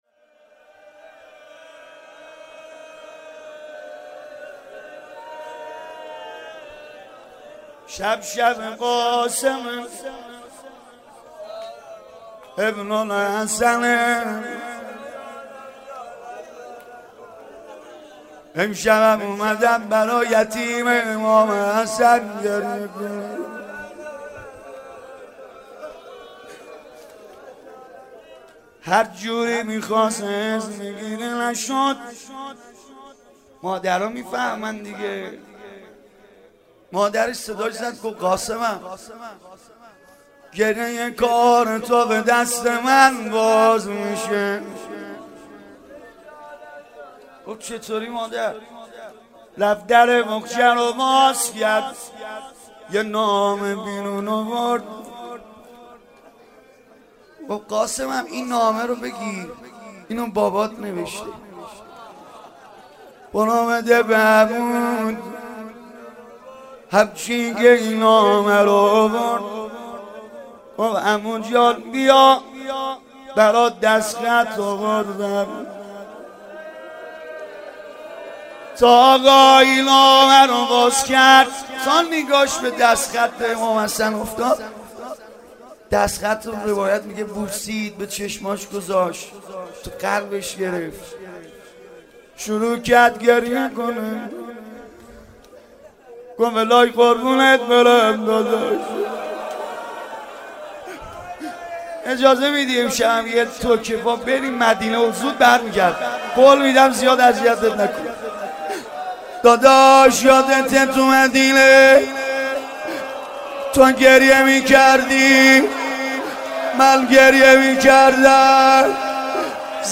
روضه حضرت قاسم